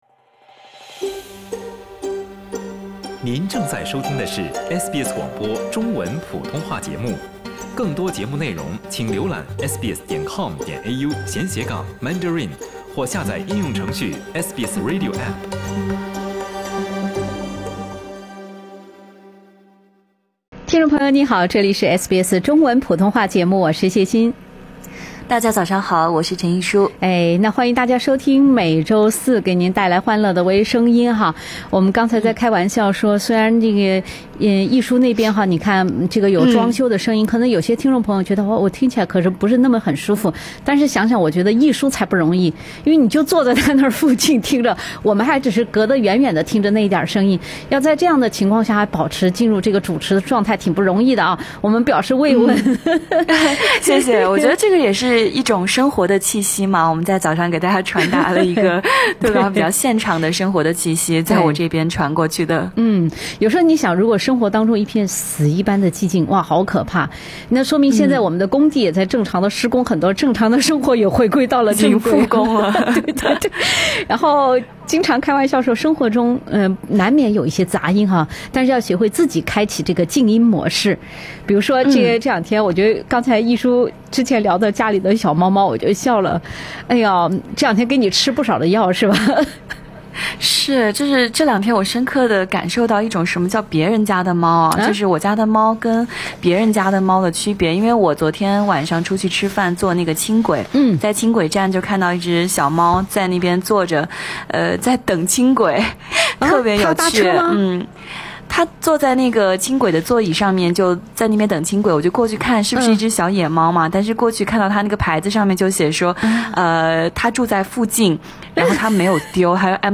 收听《微声音》，学“最时髦”的中文。本期《微声音》，在装修队的“伴奏”下，愉快开始。